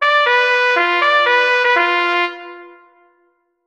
Bugle Call, Bersaglieri Corps (Italian Army) - Half distance